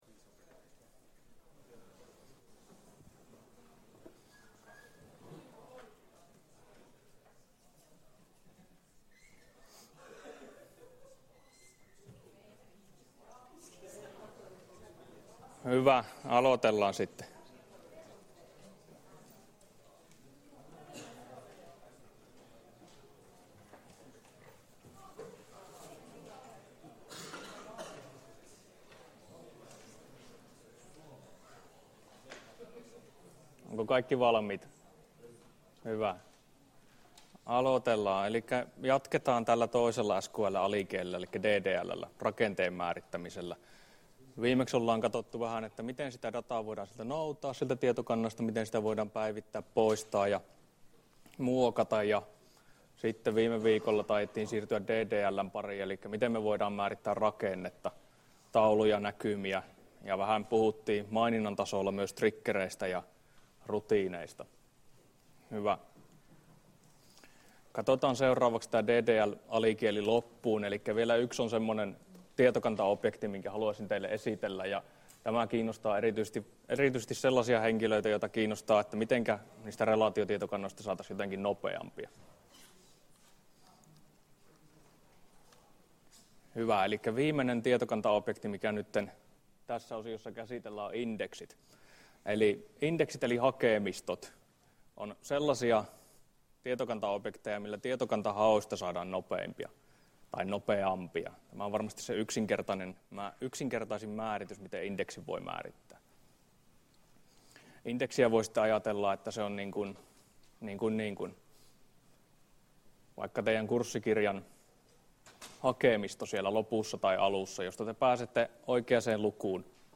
Luento 9 — Moniviestin